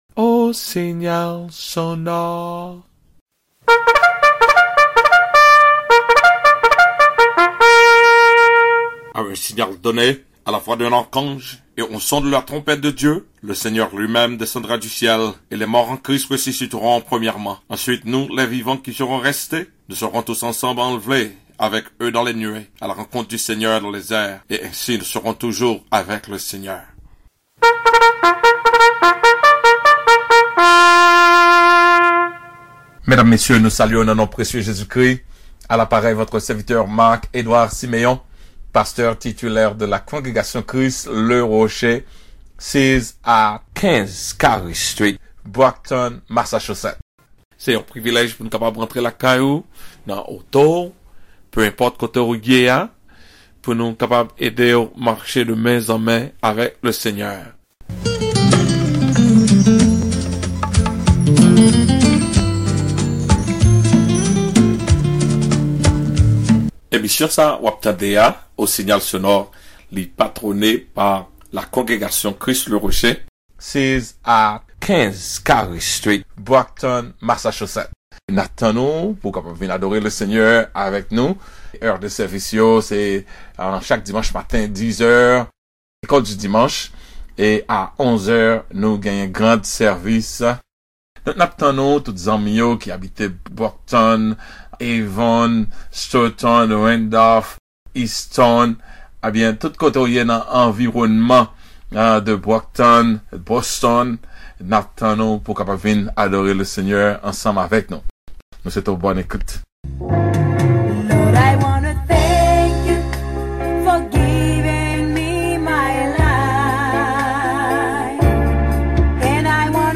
PEZE (PLAY) PO’W KA TANDE MESAJ LA AN KREYOL